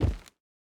added stepping sounds
Rubber_05.wav